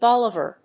音節bol・i・var 発音記号・読み方
/bəlíːvɚ(米国英語), bˈɔlɪvὰː(英国英語)/